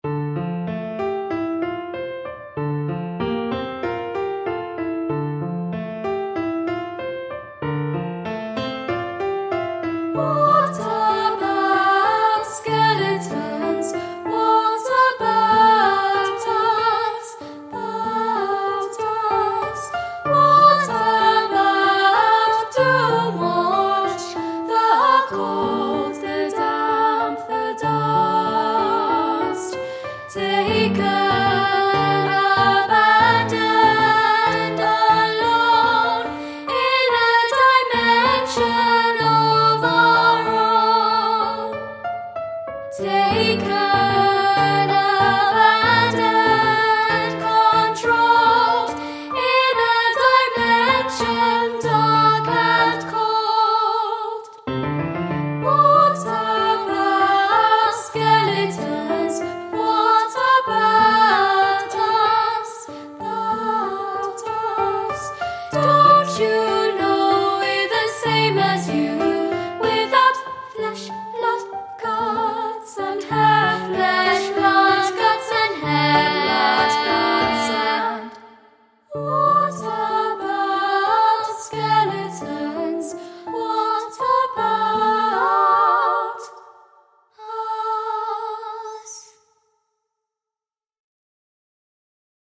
The music offers solos, part singing and chorus work.